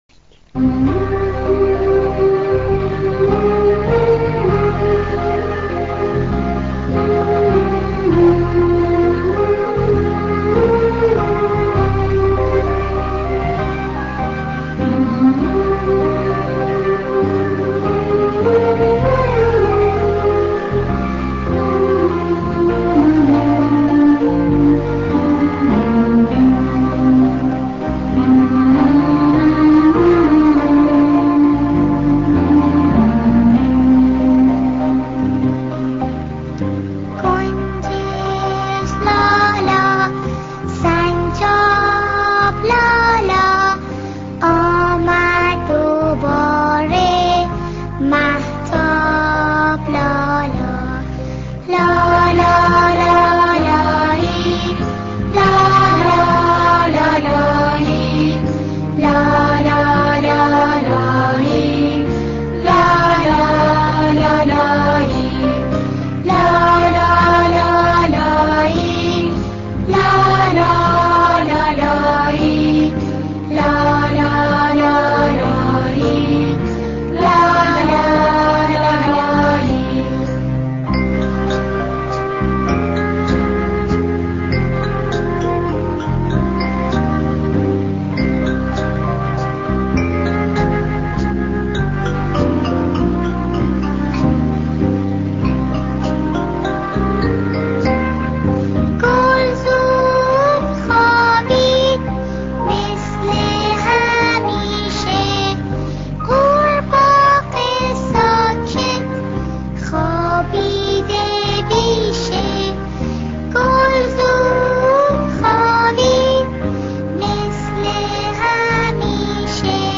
لالایی
آهنگ لالایی